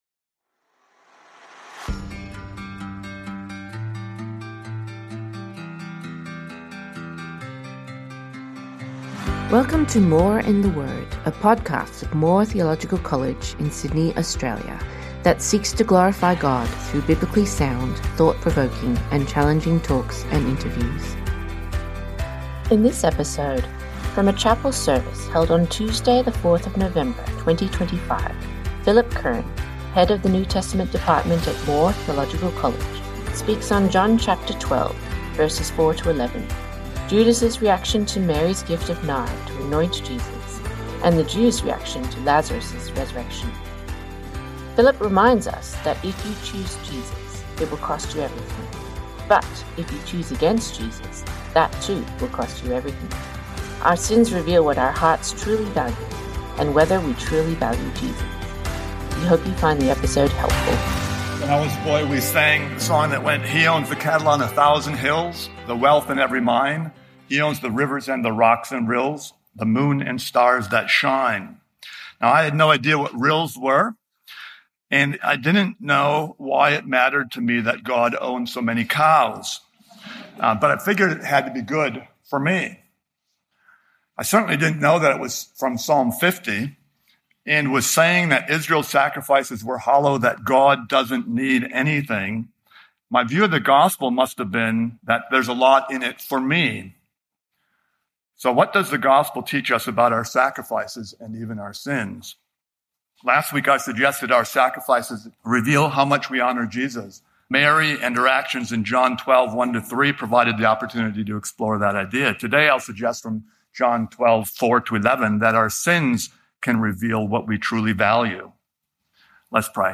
from a chapel service